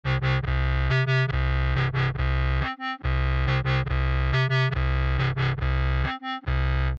杜比斯佩克合成器低音
描述：dubstep synth bass
标签： 140 bpm Dubstep Loops Bass Loops 1.18 MB wav Key : Unknown
声道立体声